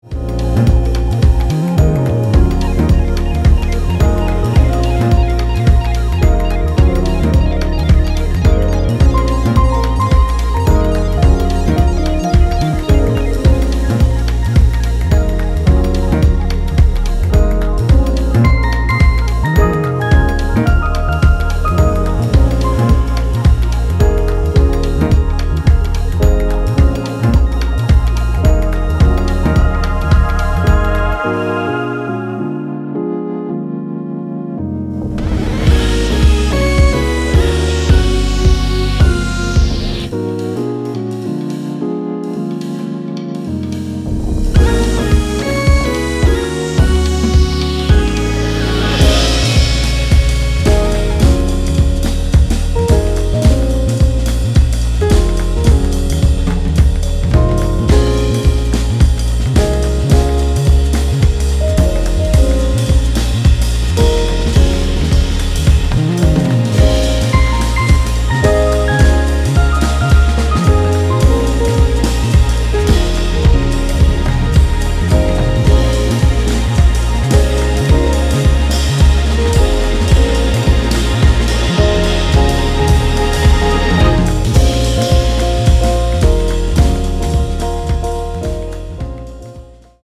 ジャンル(スタイル) NU JAZZ